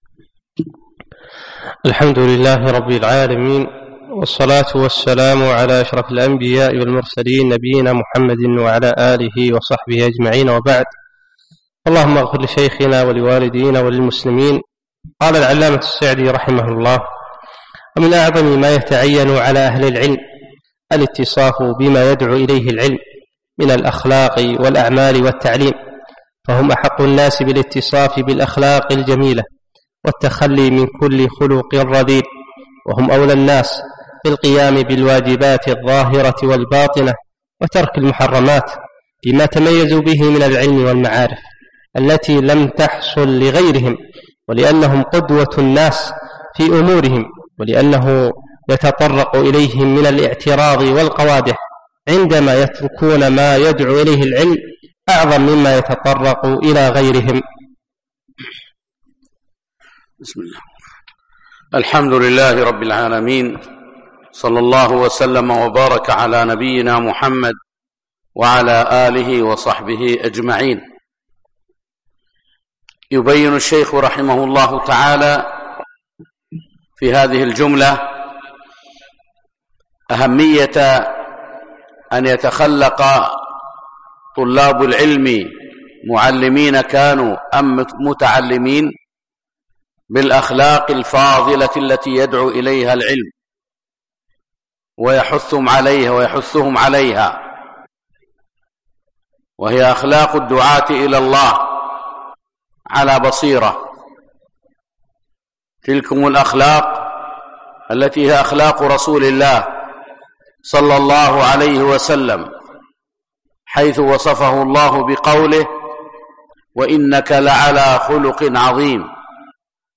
فجر الأربعاء 5 4 2017 مسجد صالح الكندري صباح السالم